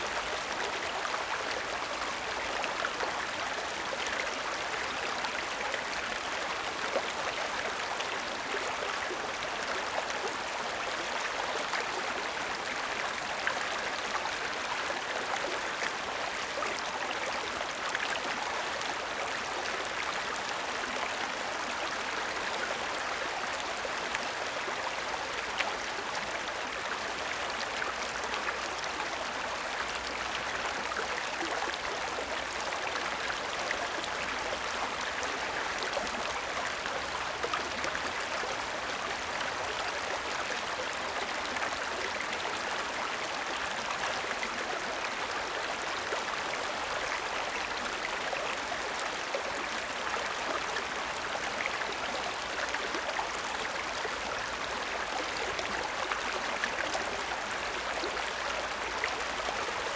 Ambiance_Nature_River_Moderate_Loop_Stereo.wav